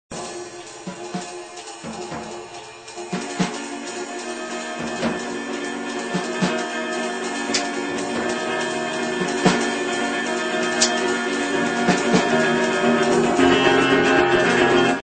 improvisations